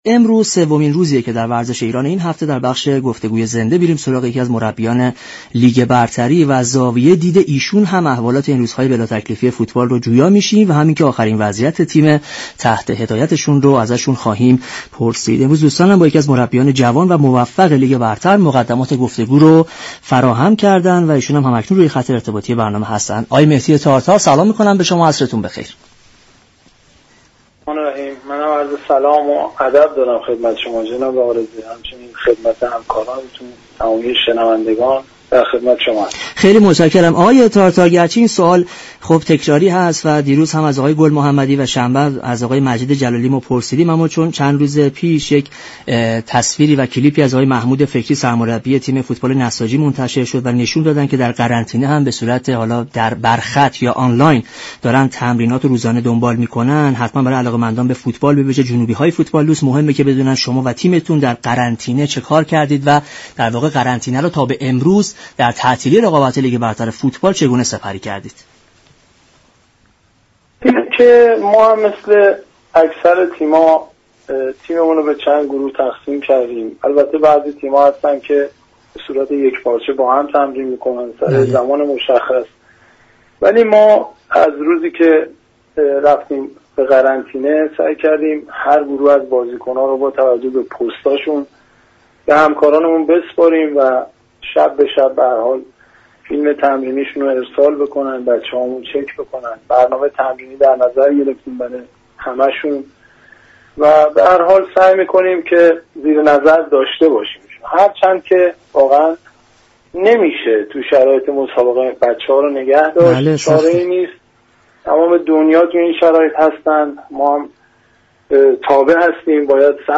برنامه ورزش ایران شنبه تا پنج شنبه هر هفته ساعت 18:00 از رادیو ایران پخش می شود.